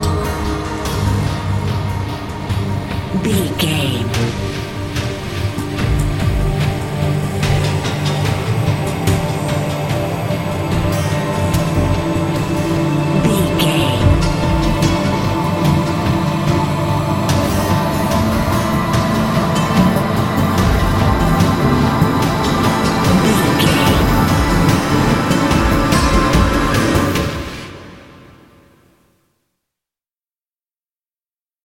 Thriller
Aeolian/Minor
Slow
strings
drums
cello
violin
percussion